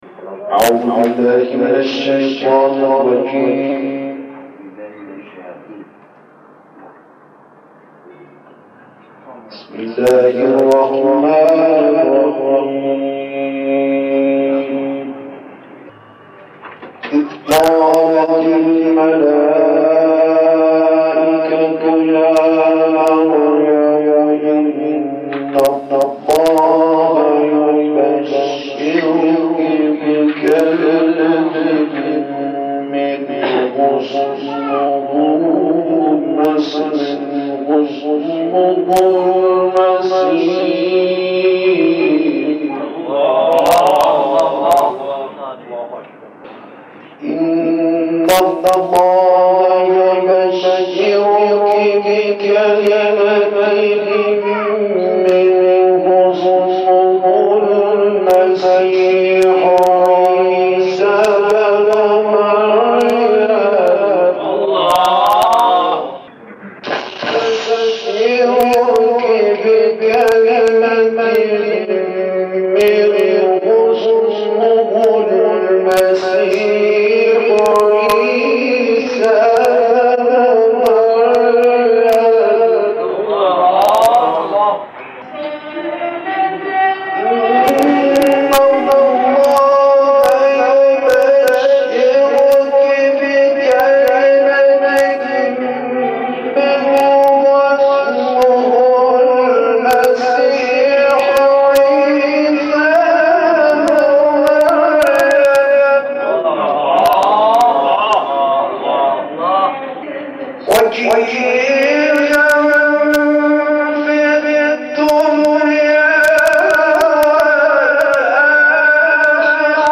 از طرفی ایشان بسیار شوخ و خوش‌صدا هم بود و جنس صدایشان همان جنس صدای مداح‌های آذری‌زبان بود.
صدای گرمی هم داشت و همین موجب شده بود تا در زمینه ابتهال بتواند فعالیت بیشتری داشته باشد.
ابتهال ، تلاوت قرآن